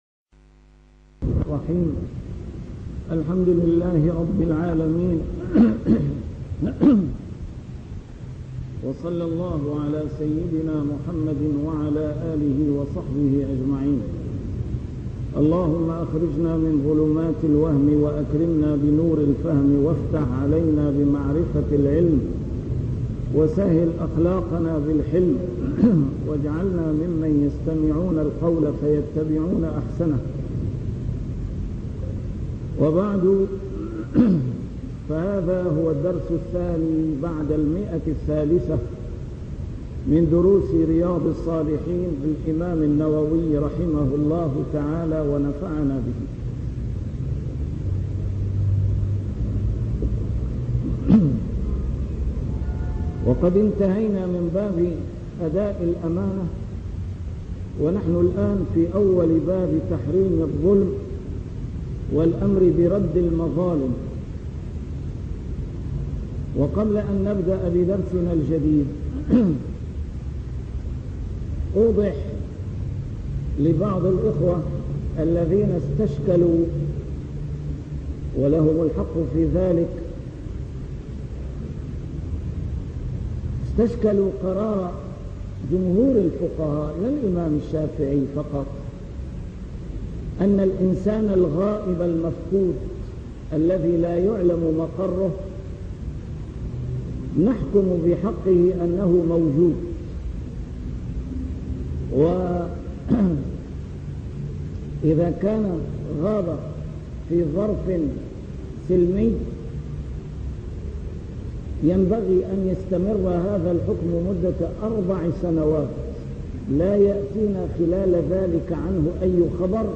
A MARTYR SCHOLAR: IMAM MUHAMMAD SAEED RAMADAN AL-BOUTI - الدروس العلمية - شرح كتاب رياض الصالحين - 302- شرح رياض الصالحين: الأمر بأداء الأمانة